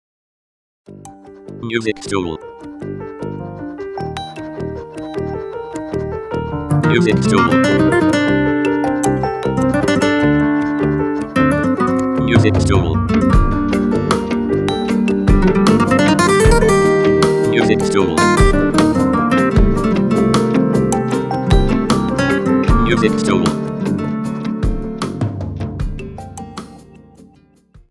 • Type : Instrumental /Aufio Track
• Bpm : Andante
• Genre : Rock / R&B